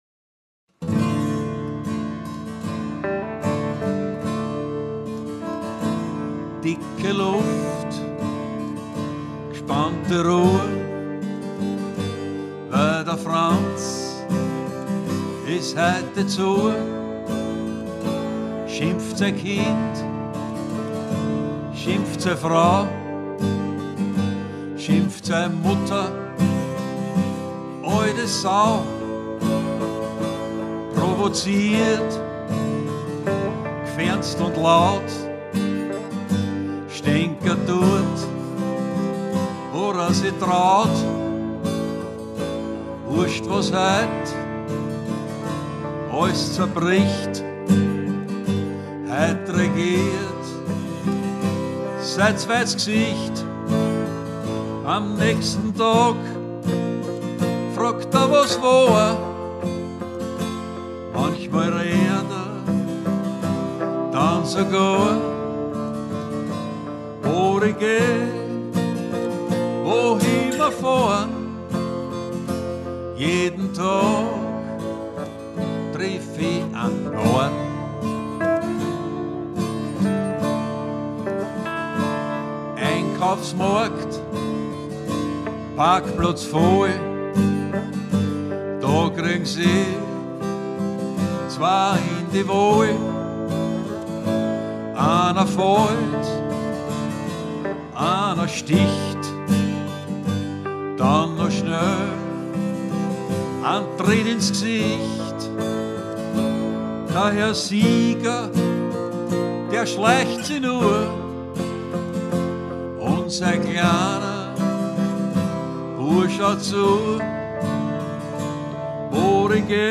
Dialektliedermacher
Top-Gitarrist